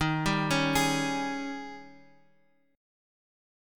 Eb11 Chord
Listen to Eb11 strummed